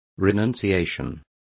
Complete with pronunciation of the translation of renunciations.